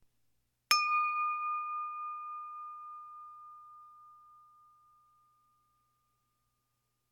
Wine glass 2
bell chime crystal ding wine-glass sound effect free sound royalty free Sound Effects